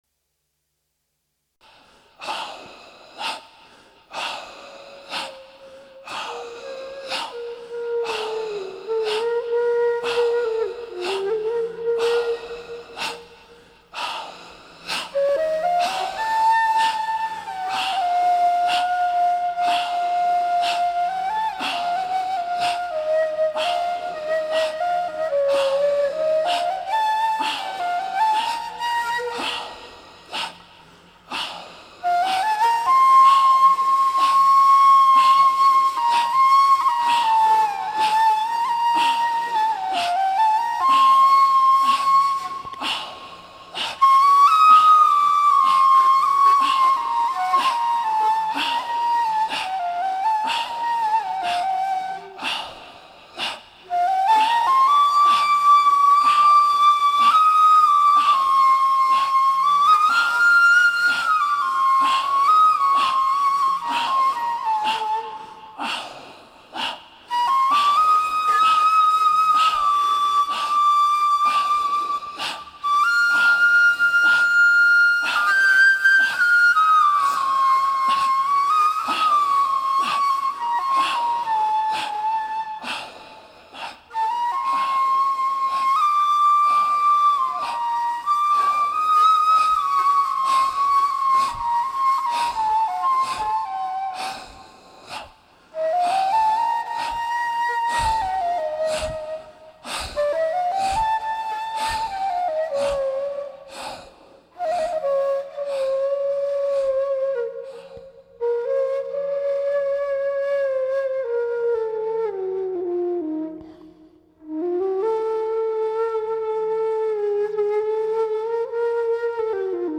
devotional sacred spiritual middle-eastern meditative
sufi music flute flutes